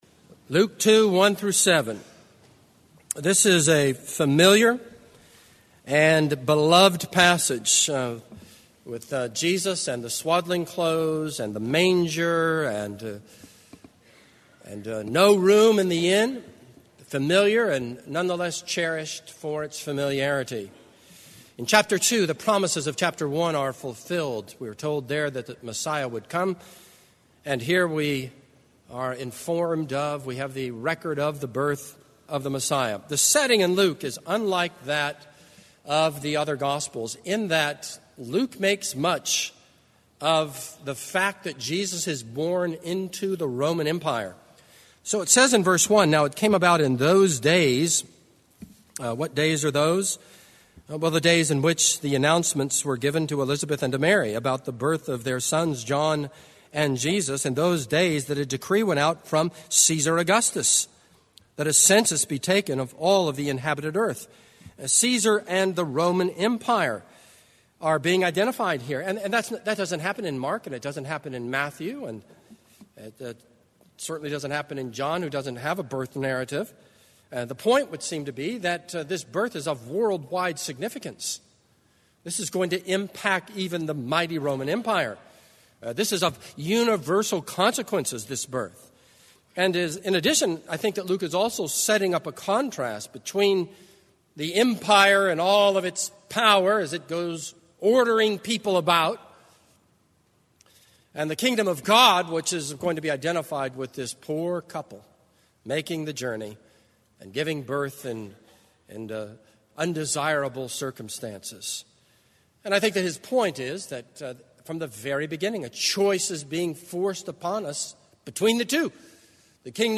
This is a sermon on Luke 2:1-7.